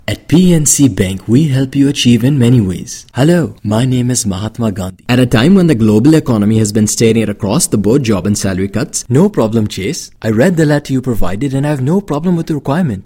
A unique voice that can be tailored to ensure the effective delivery of a variety of tones ranging from authoritative to friendly for a wide range of products and services.
Sprechprobe: Werbung (Muttersprache):